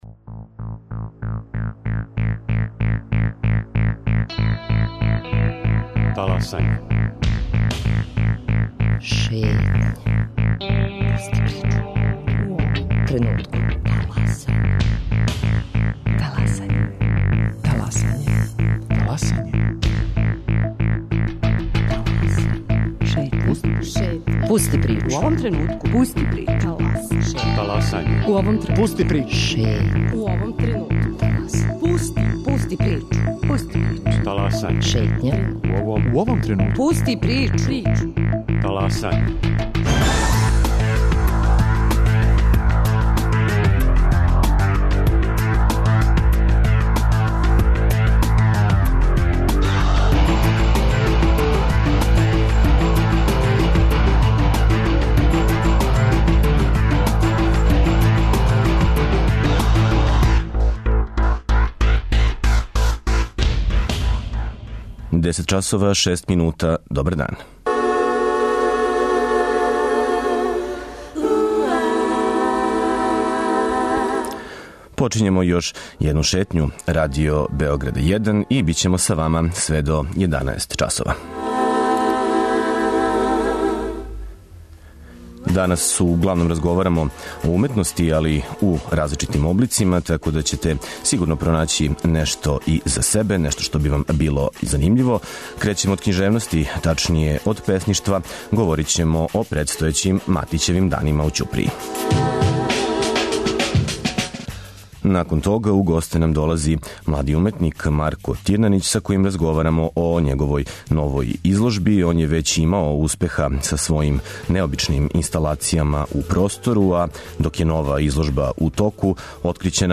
За крај Шетње, ту је репортерска слика из Ниша.